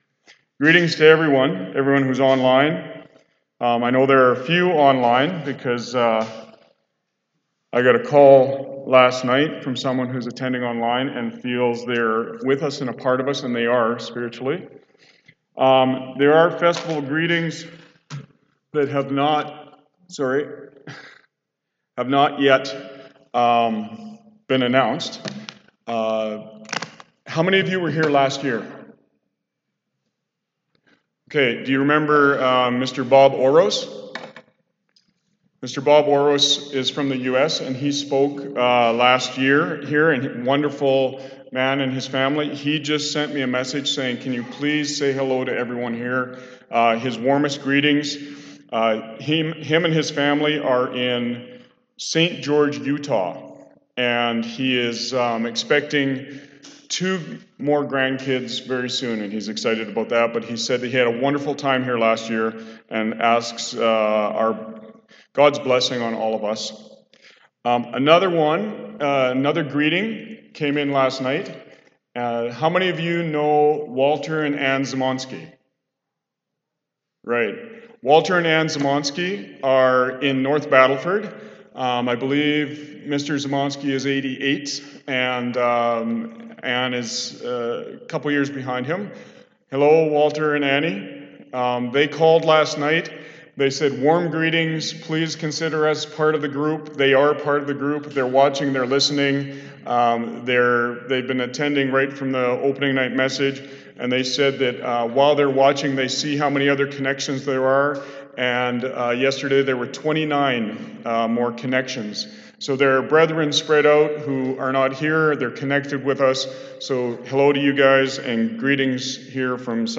This sermon was given at the Cochrane, Alberta 2020 Feast site.